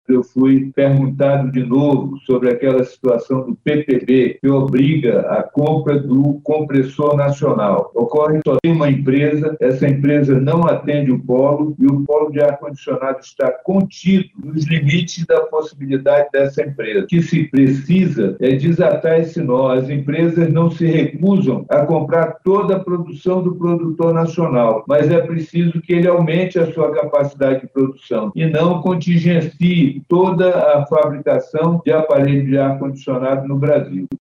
A reunião de número 320 do Conselho de Administração da Suframa – CAS ocorreu na manhã desta quarta-feira 27/08 por videoconferência.
Durante a reunião, o secretário de Estado de Desenvolvimento Econômico, Ciência, Tecnologia e Inovação, Serafim Corrêa, voltou a pedir providências sobre as fabricantes de ar-condicionados que enfrentam dificuldades na aquisição de compressores.
SONORA-2-REUNIAO-CAS-.mp3